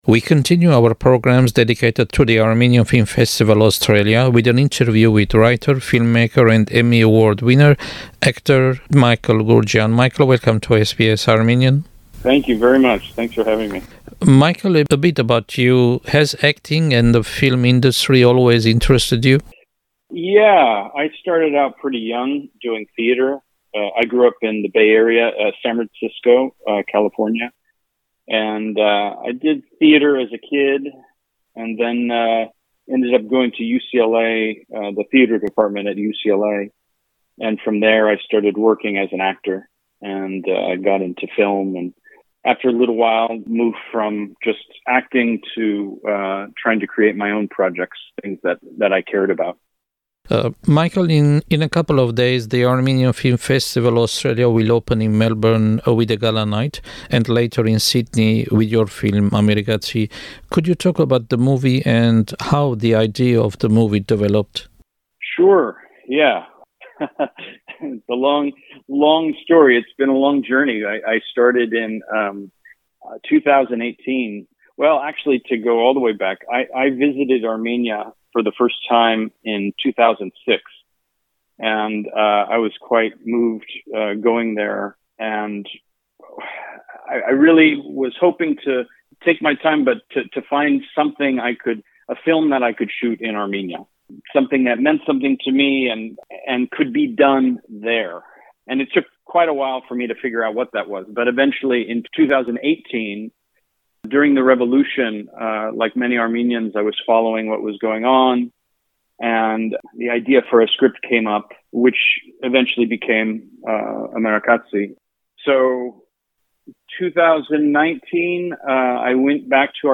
Interview with writer, filmmaker, and Emmy Award winner for Outstanding Supporting Actor, Michael Goorjian, about his movie Amerikatsi (The American).